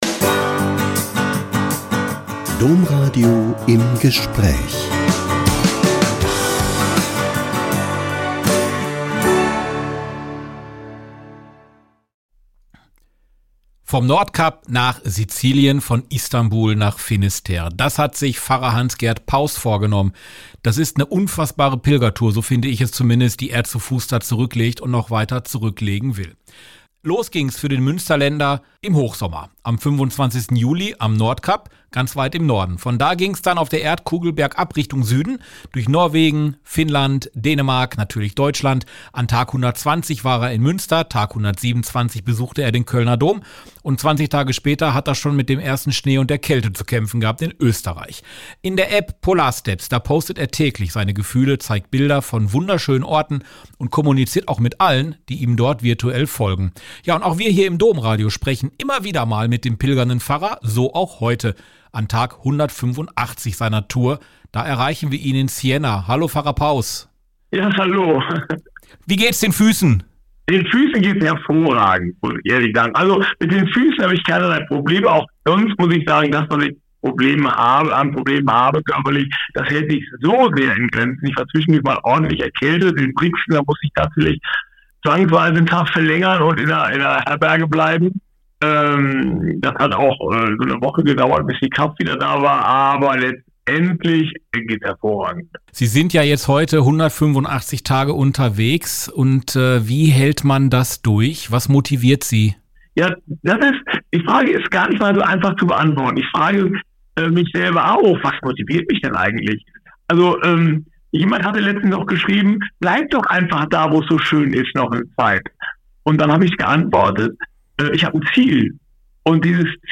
Wir verfolgen seine Pilgerreise und sprechen regelmäßig mit